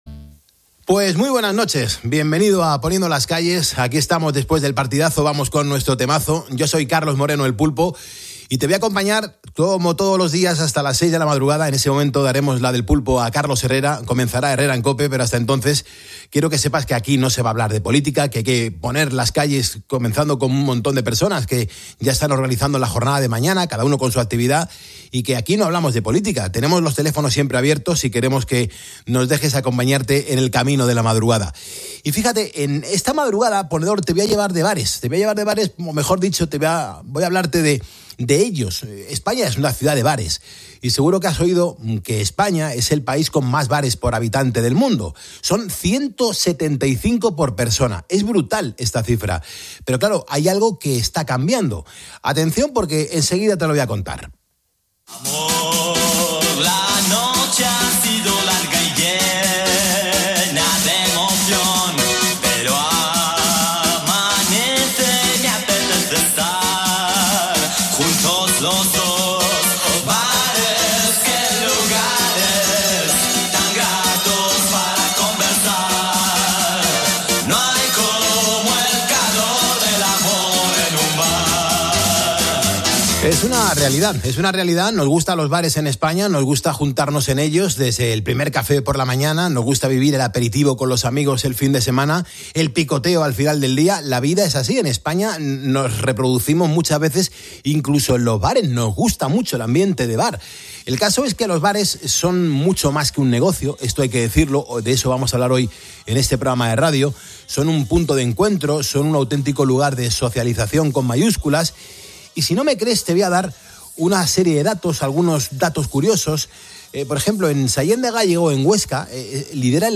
En el programa 'Poniendo las Calles' de la Cadena COPE